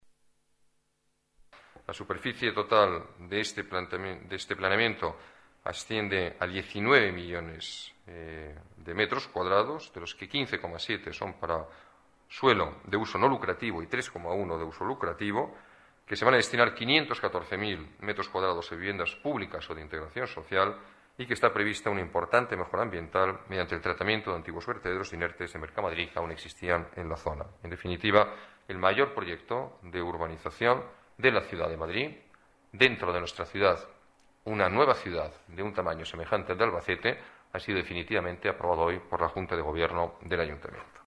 Nueva ventana:Declaraciones alcalde, Alberto Ruiz-Gallardón: desarrollo urbanístico Valdecarros